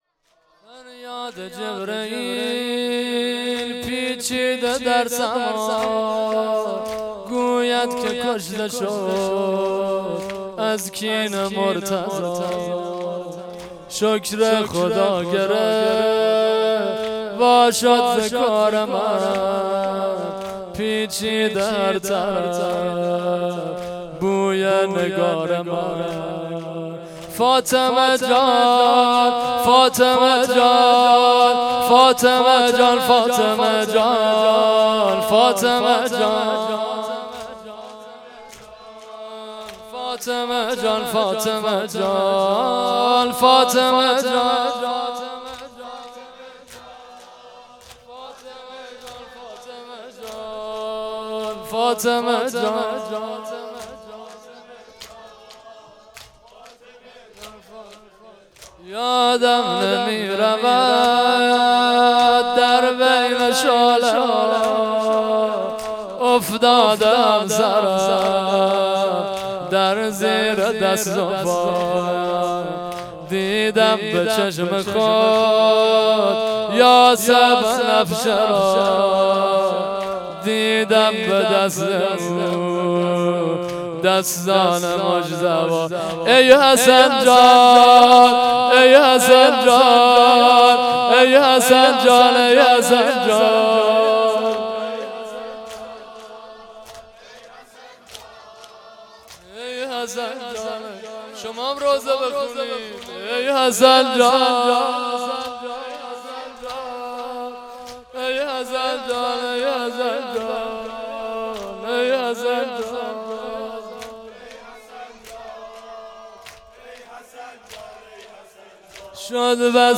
سینه زنی
sine-zani1.mp3